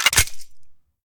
select-machine-gun-1.ogg